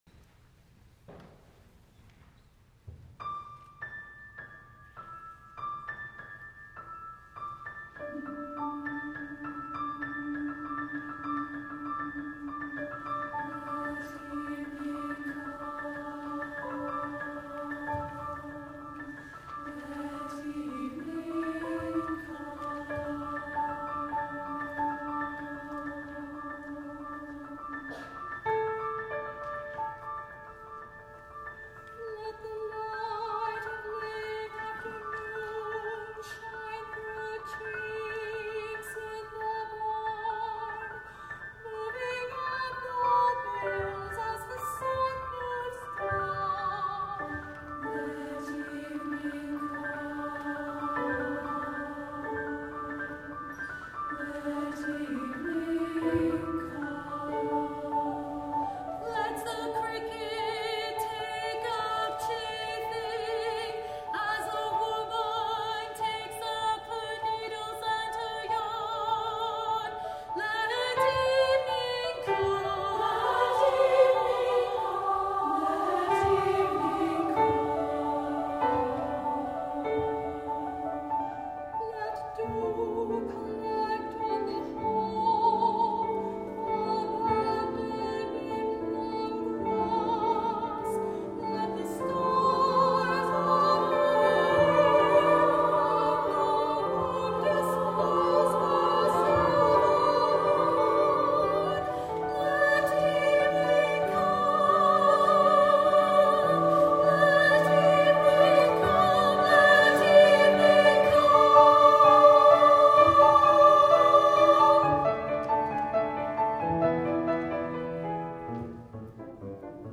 for SSA Chorus and Piano (2001)